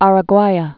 (ärə-gwīə)